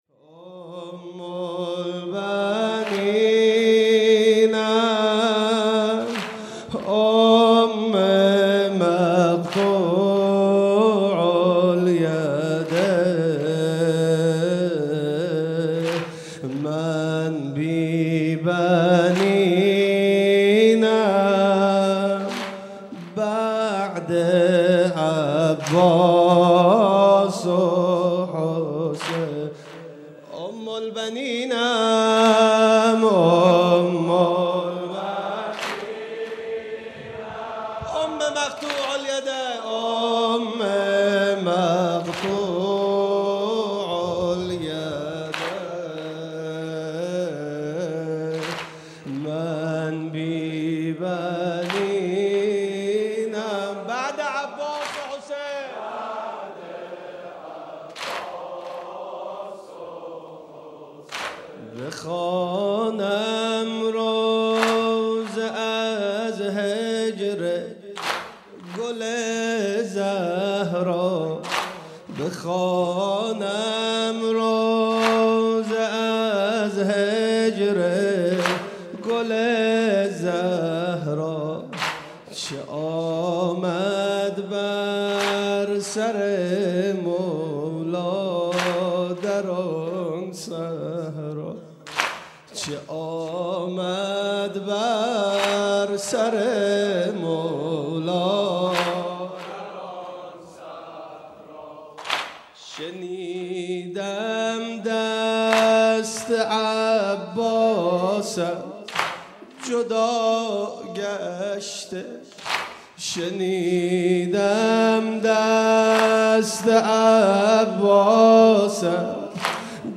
واحد مداحی